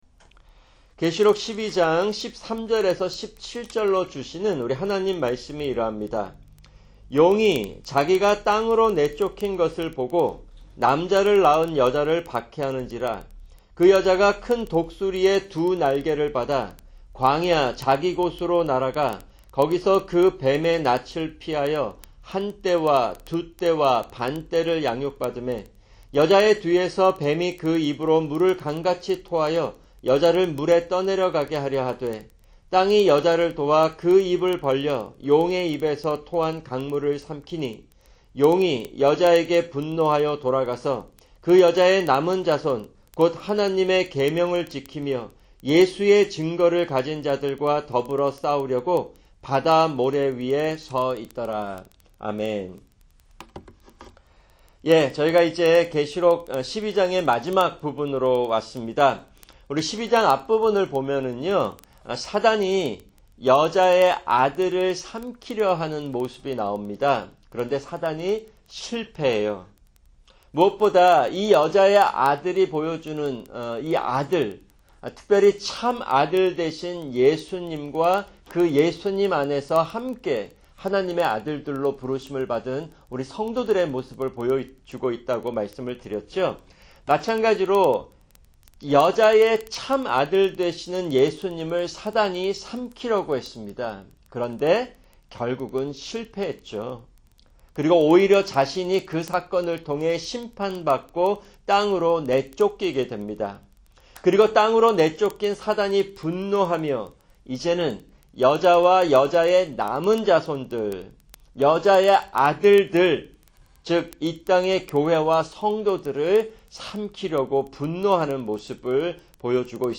[금요 성경공부] 계시록 12:13-17(1)